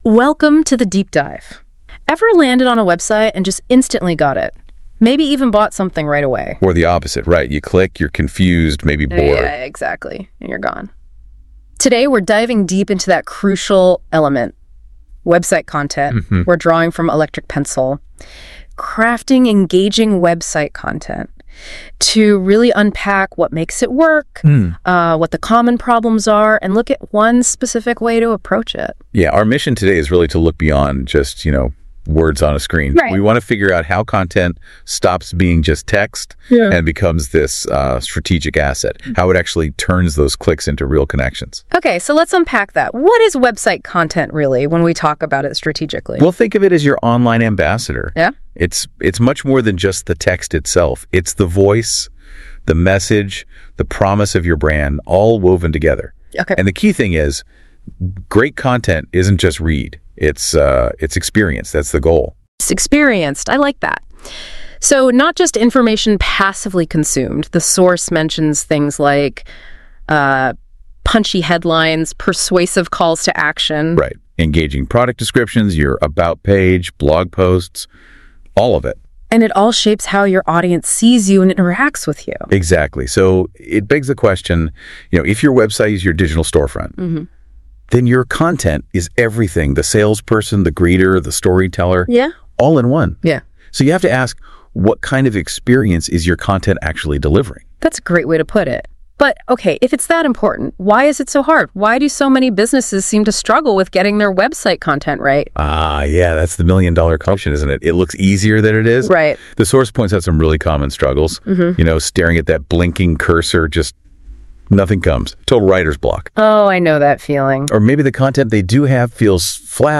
In this NotebookLM audio deep dive, we explore how to stay human, clear, and strategic in an AI-driven content world.